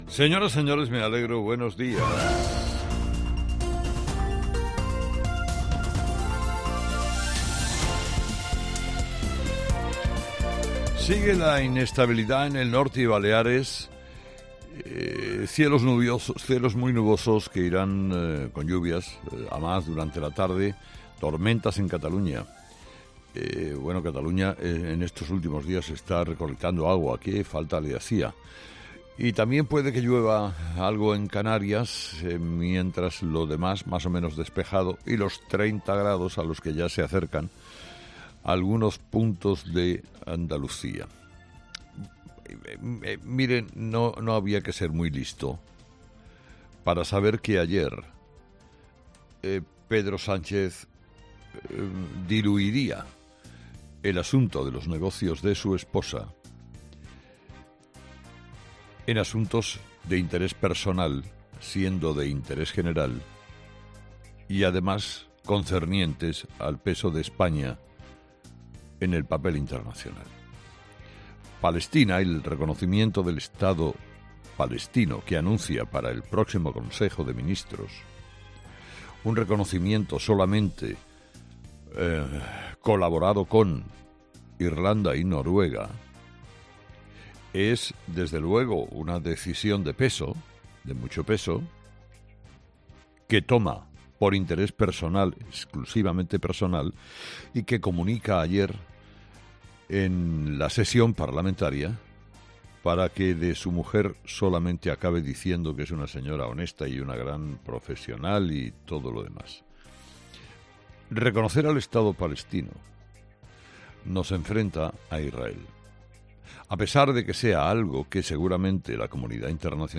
Carlos Herrera, director y presentador de 'Herrera en COPE', comienza el programa de este jueves analizando las principales claves de la jornada que pasan, entre otras cosas, por las explicaciones de Pedro Sánchez en el Congreso.